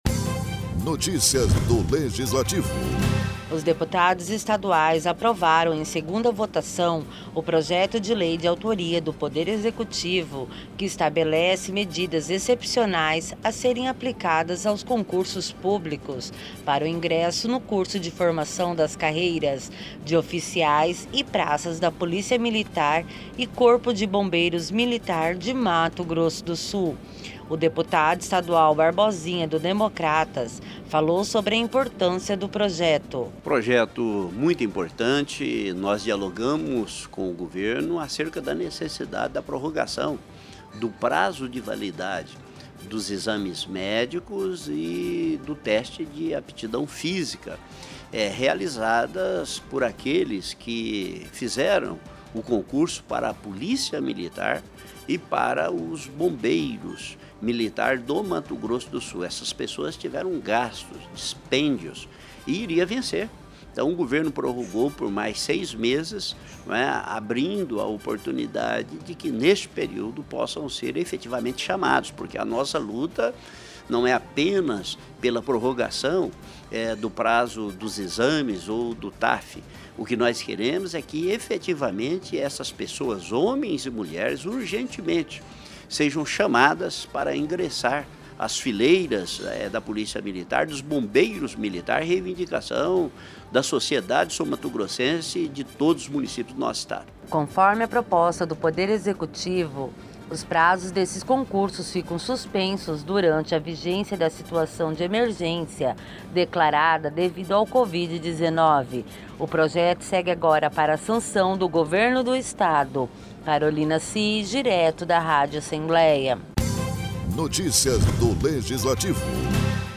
Locução e Produção: